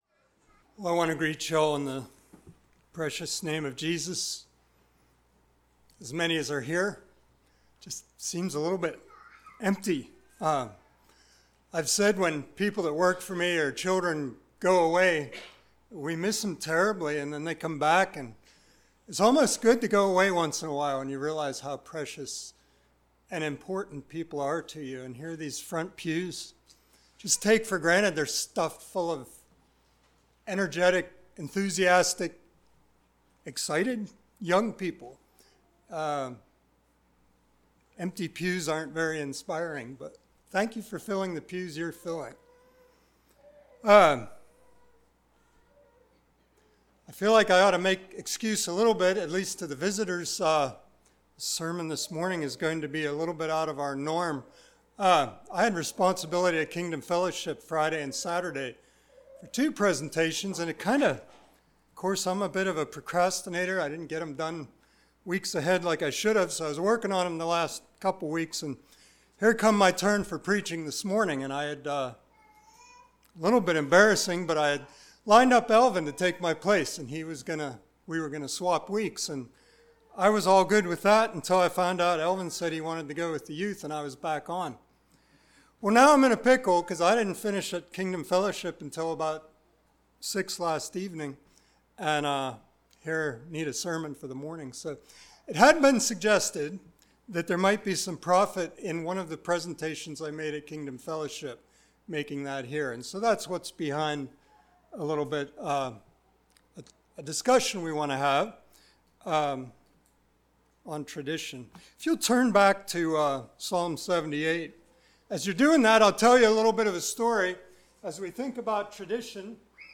Service Type: Sunday Morning Topics: Tradition , Traditionalism